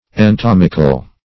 Meaning of entomical. entomical synonyms, pronunciation, spelling and more from Free Dictionary.
Search Result for " entomical" : The Collaborative International Dictionary of English v.0.48: Entomic \En*tom"ic\, Entomical \En*tom"ic*al\, a. [Gr.